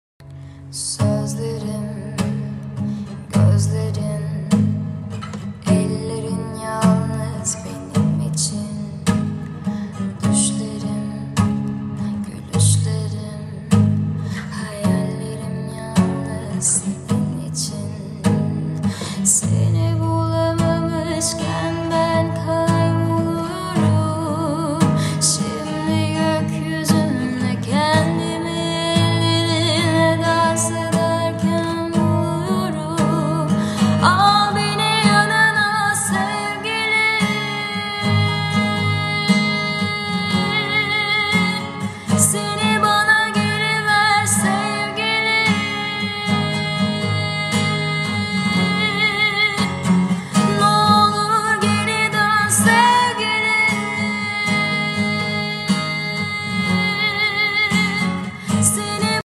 با صدای دختر